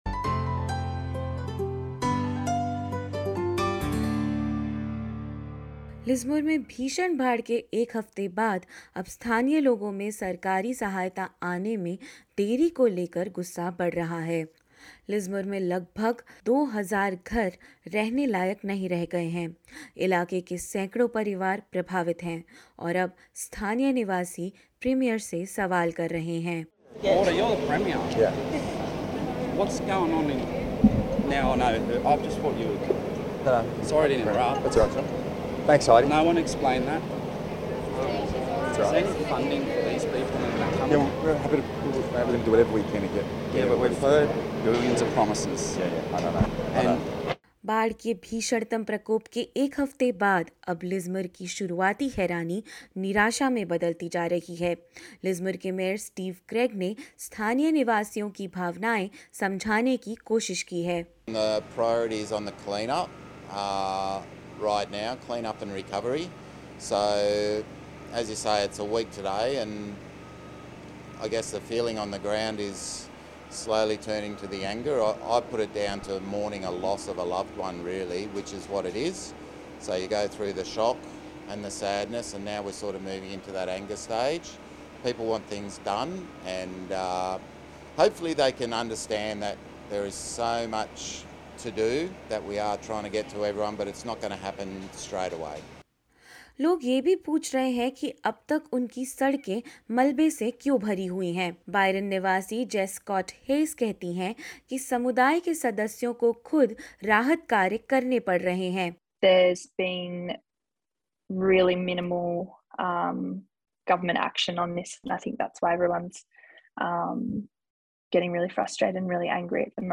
क्या है लिज़मोर की ज़मीनी परिस्थिति, जानिए इस रिपोर्ट में।